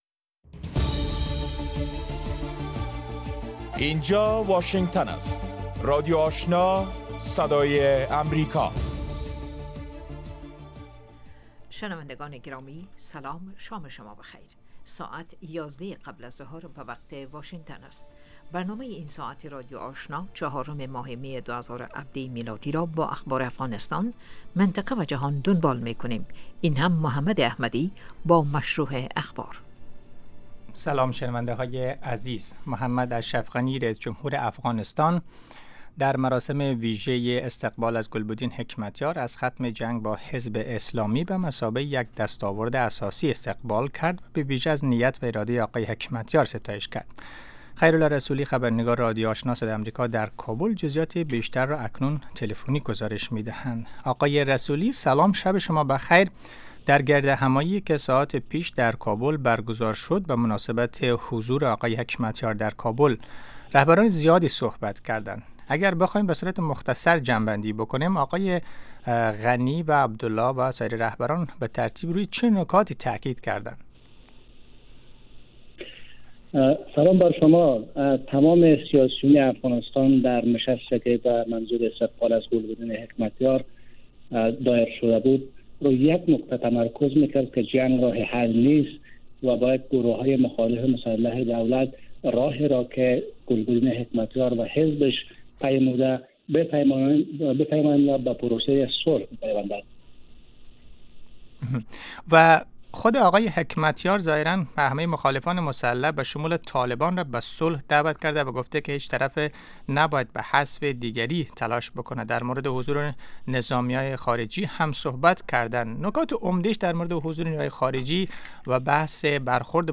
نخستین برنامه خبری شب